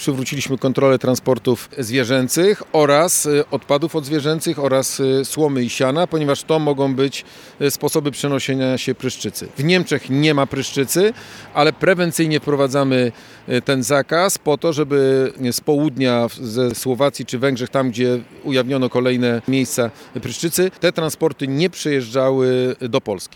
Na granicy polsko-niemieckiej w Zachodniopomorskiem ponownie wprowadzono kontrole transportów zwierząt. To reakcja na ogniska pryszczycy, które wykryto w niektórych krajach Europy – informuje wojewoda zachodniopomorski Adam Rudawski.